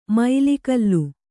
♪ maili kallu